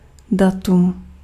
Ääntäminen
Ääntäminen France: IPA: [dat] Haettu sana löytyi näillä lähdekielillä: ranska Käännös Ääninäyte Substantiivit 1. datum {n} 2. termín {m} Suku: f .